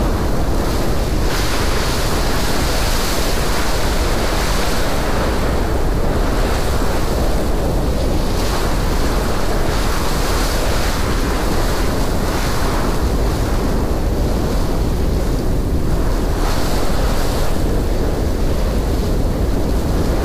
Wind5.ogg